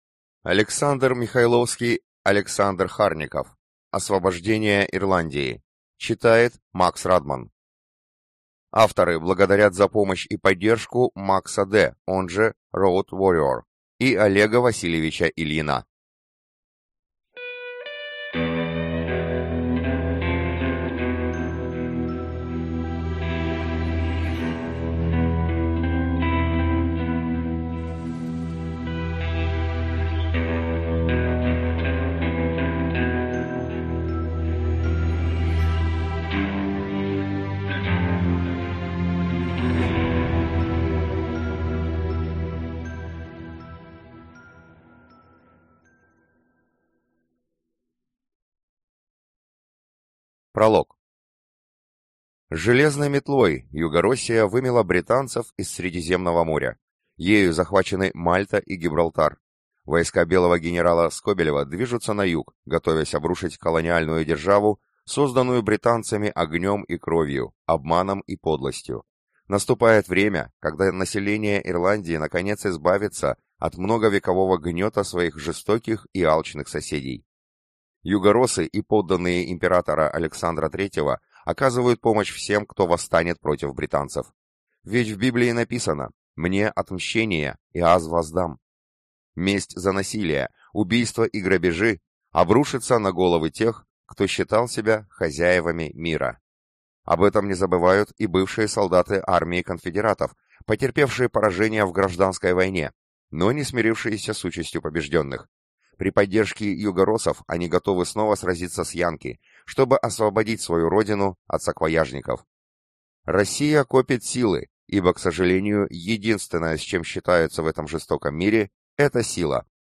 Аудиокнига Освобождение Ирландии | Библиотека аудиокниг